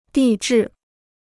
地质 (dì zhì): geology.